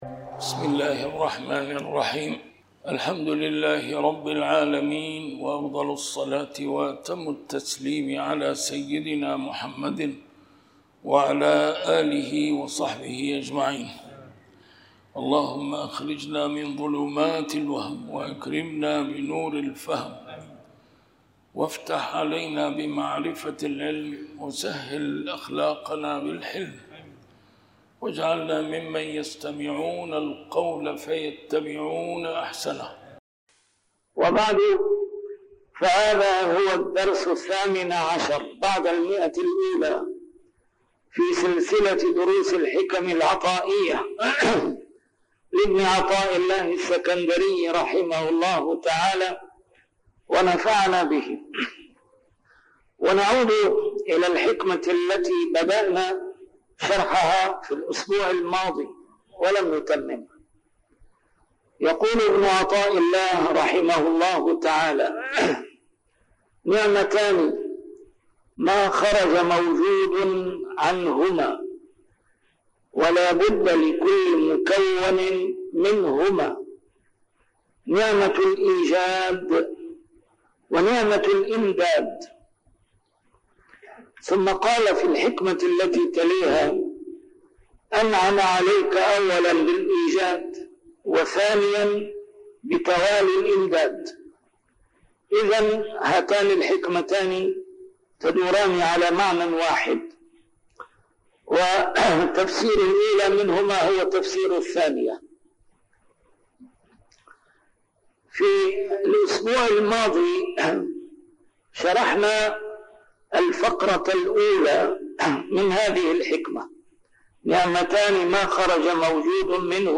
شرح الحكم العطائية - A MARTYR SCHOLAR: IMAM MUHAMMAD SAEED RAMADAN AL-BOUTI - الدروس العلمية - علم السلوك والتزكية - الدرس رقم 118 شرح الحكمة 97+98